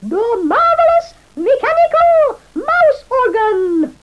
The marvellous, mechanical, mouse organ!